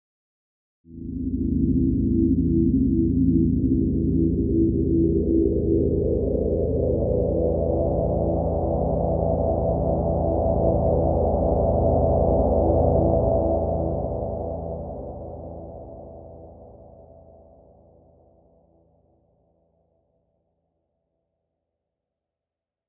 描述：一个上升的合成器声音。来自我的介绍和简短的合成器声音包。
Tag: 气氛 气氛 电子 介绍 音乐 处理 合成器